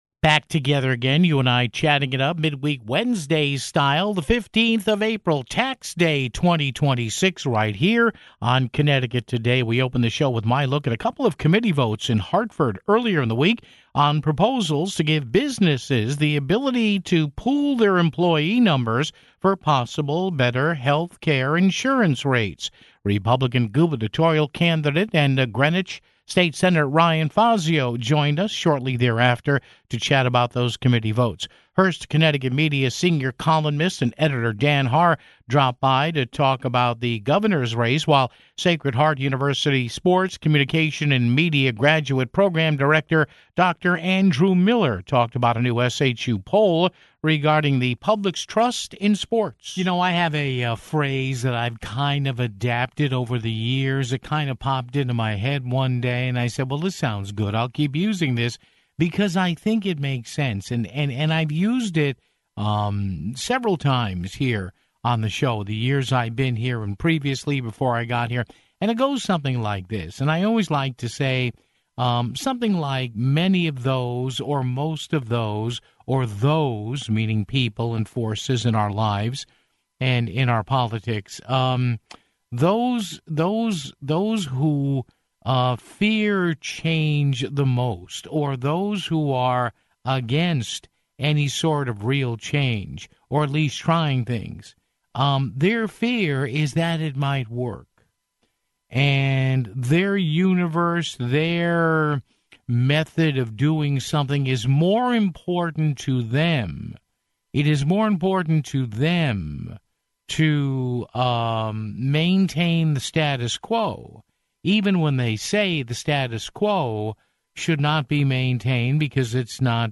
GOP Gubernatorial candidate and Greenwich State Senator Ryan Fazio joined us to chat about those committee votes (16:11).